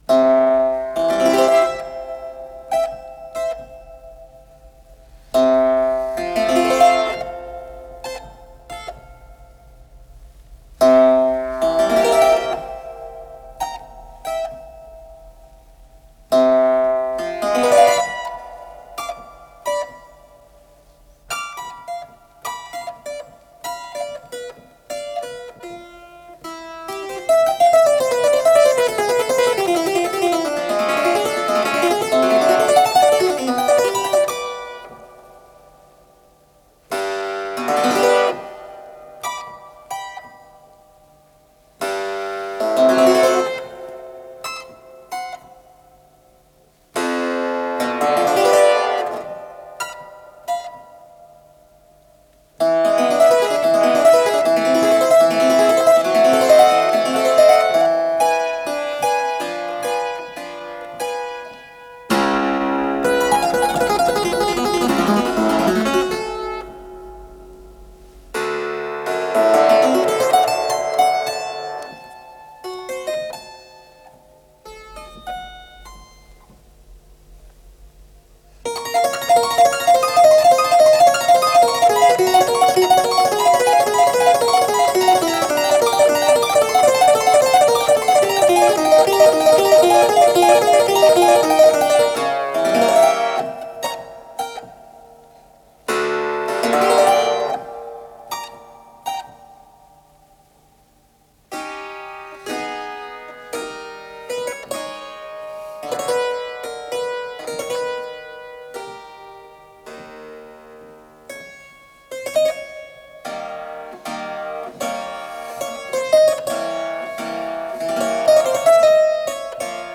с профессиональной магнитной ленты
ПодзаголовокДо мажор
ИсполнителиАлексей Любимов - клавикорд
ВариантДубль моно